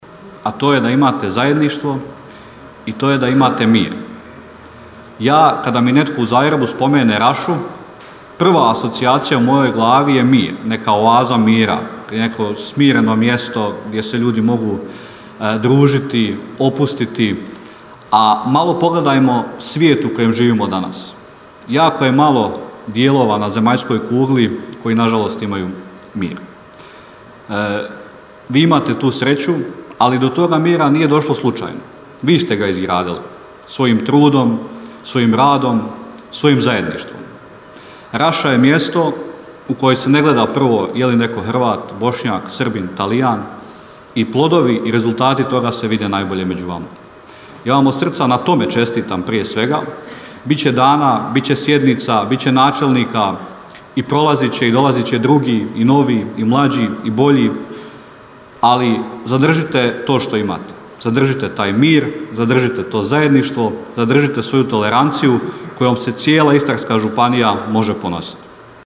Svečanom sjednicom Općinskog vijeća Općina Raša jučer je proslavila svoj dan.